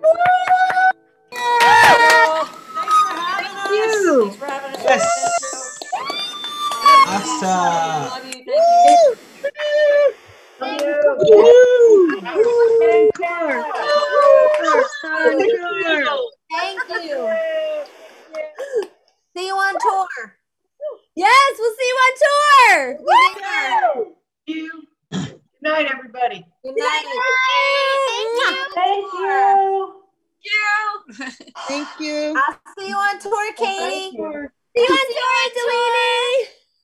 (captured from the zoom livestream)
08. conversation (0:36)